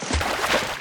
t_water4.ogg